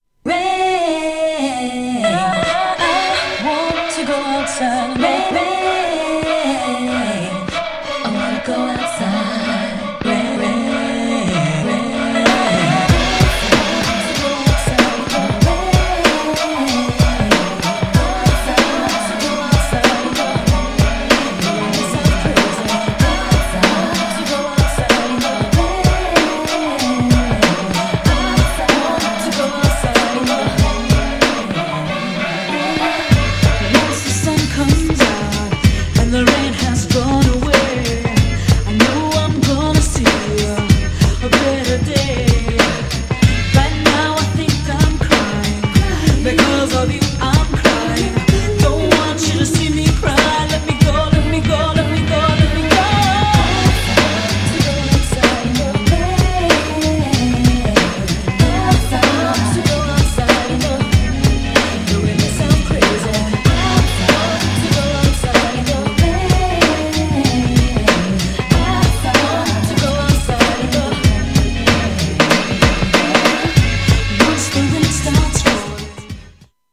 GENRE House
BPM 116〜120BPM